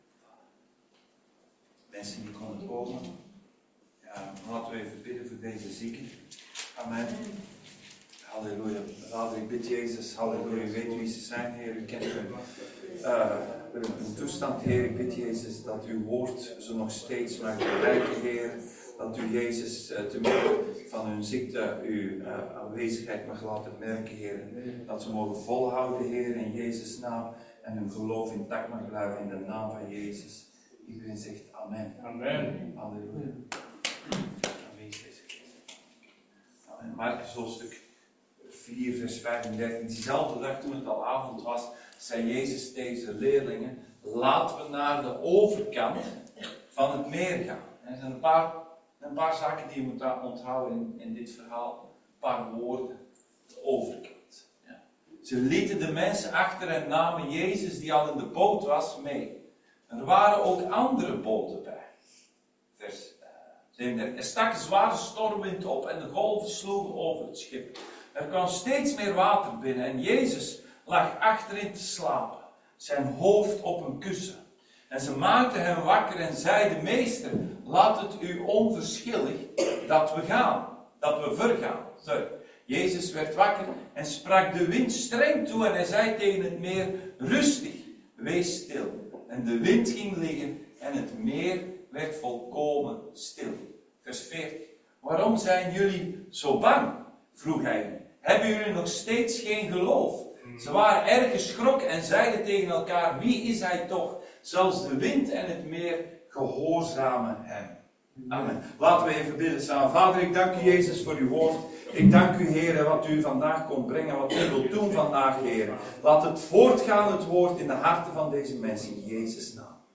Een vraag van de Heer Dienstsoort: Zondag Dienst « Boek van Hebreeën